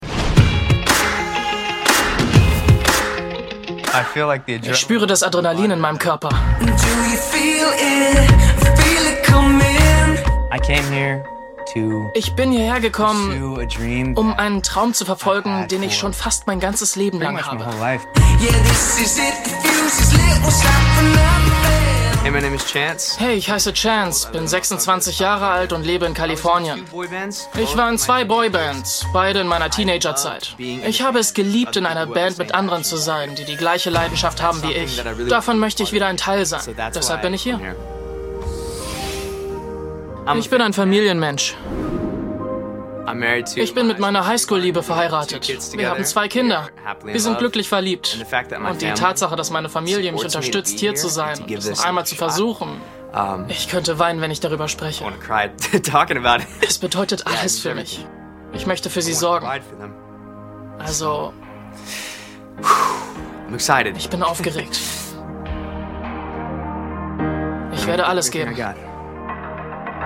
Dietmar Wunder (Berlin) spricht Hoops Dietmar Wunder ist bekannt als deutsche Stimme von Daniel Craig in James Bond - seit Casino Royale - (2006) und leiht seine Stimme u.a. den Kollegen Adam Sandler, Cuba Go... mehr daten auswählen